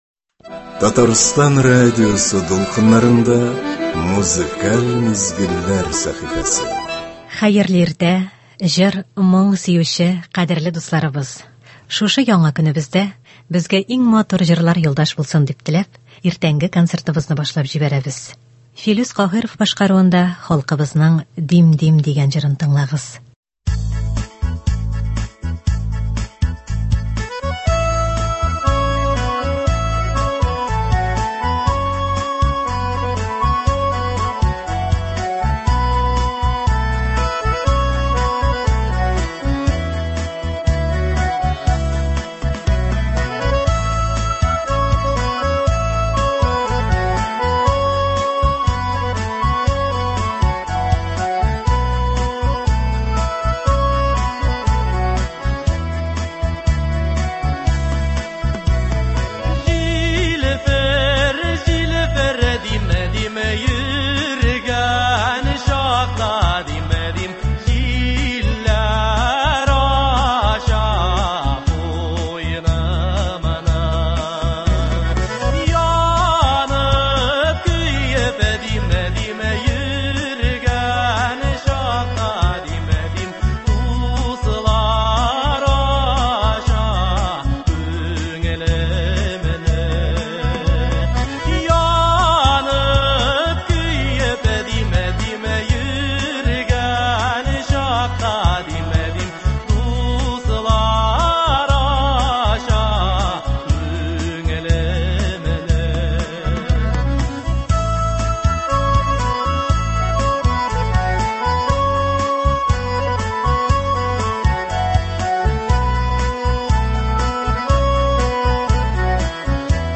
Бүгенге иртәнге концертта яраткан җырларыбыз яңгырый.